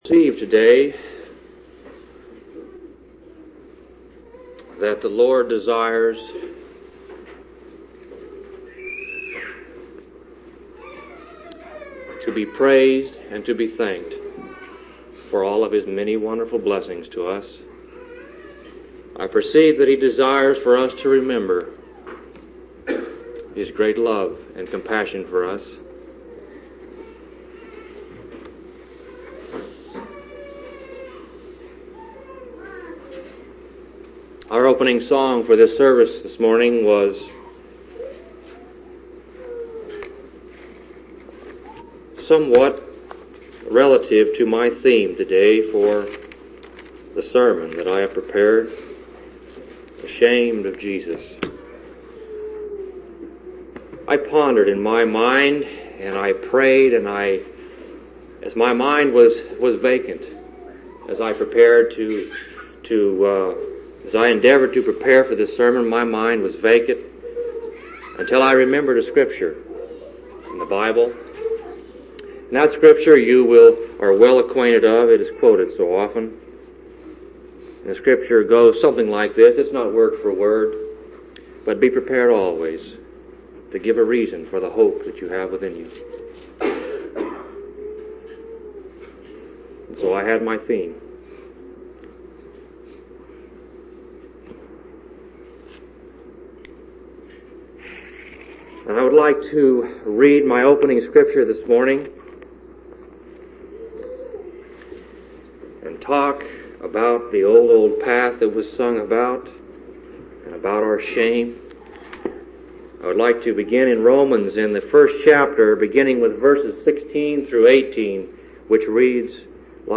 10/14/1984 Location: Phoenix Local Event